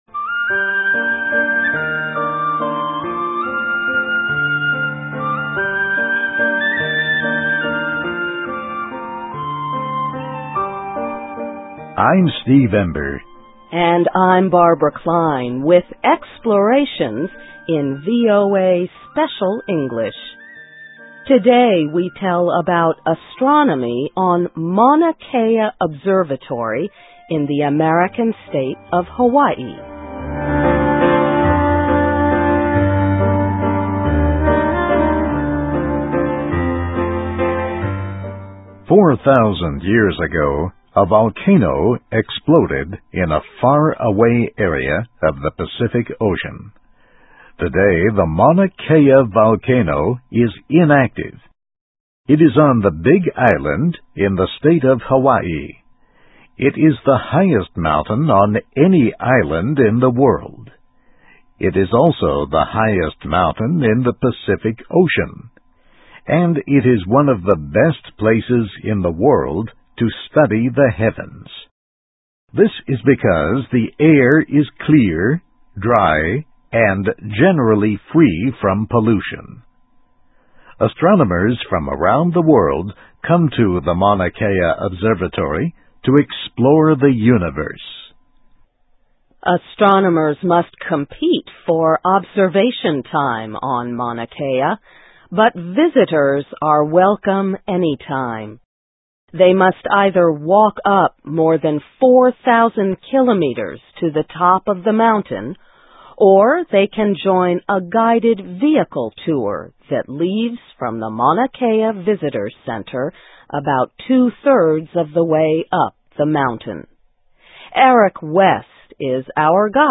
Places: Mauna Kea Observatory (In Hawaii) (VOA Special English 2005-10-04)
Listen and Read Along - Text with Audio - For ESL Students - For Learning English